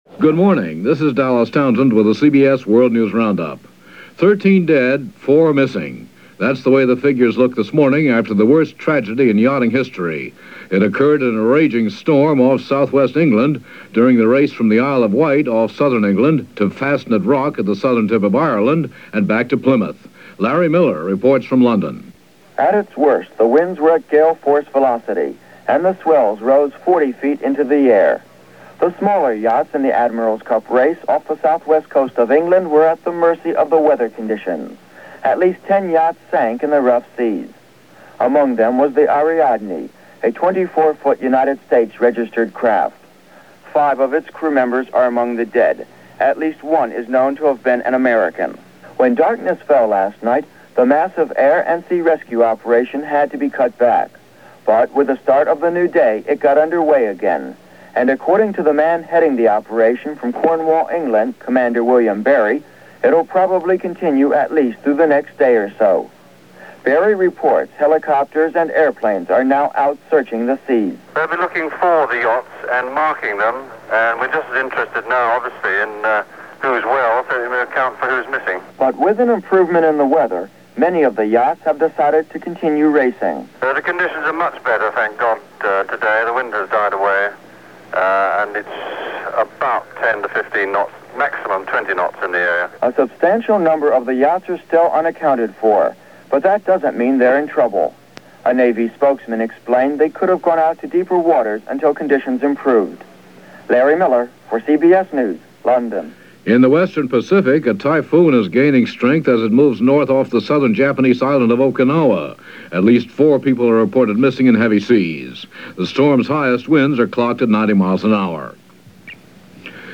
And that’s just a small slice of the complications which made up August 15, 1979, and as reported by The CBS World News Roundup.